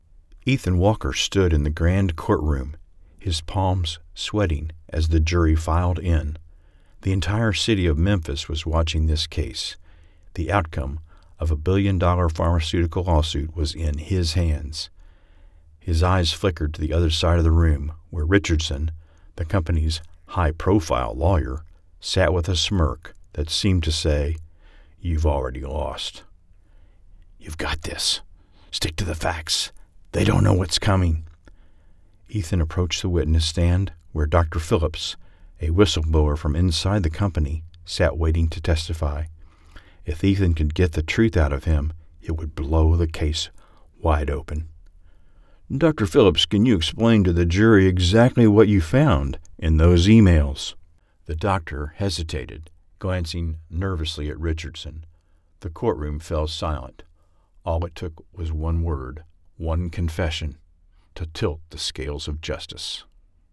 Legal Thriller audiobook
Legal-Thriller-audiobook.mp3